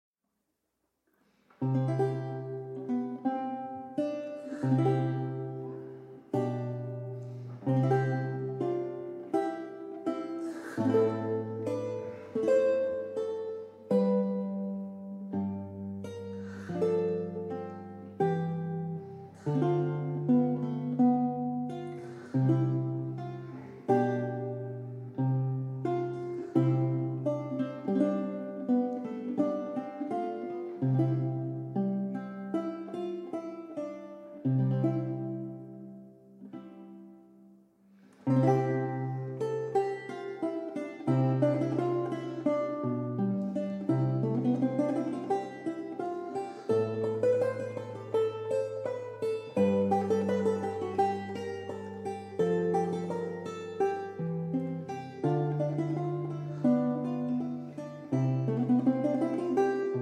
English, Scottish, and Irish lute songs